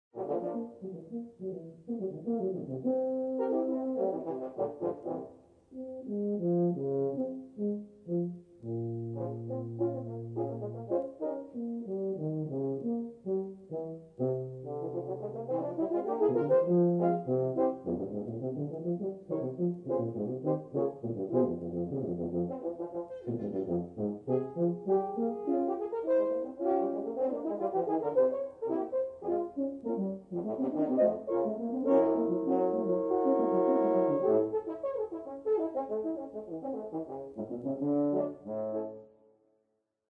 Tuba and four horns